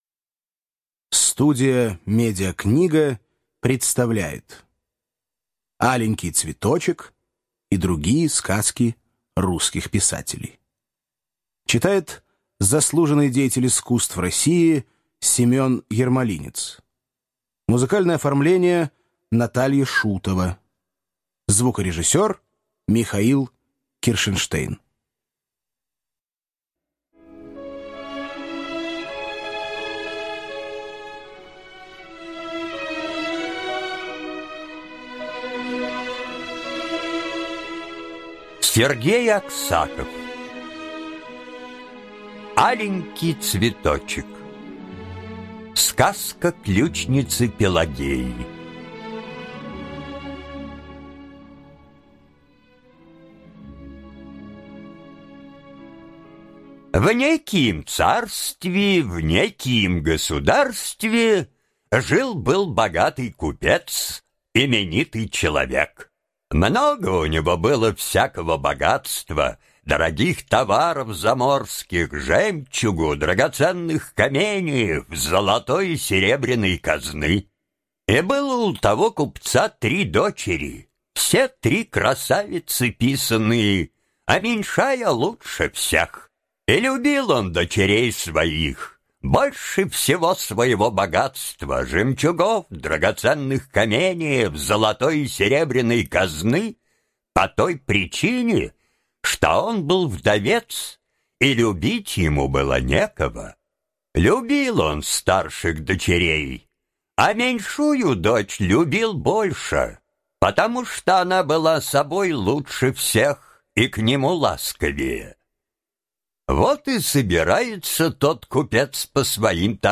Аленький цветочек - аудиосказка Аксакова - слушать онлайн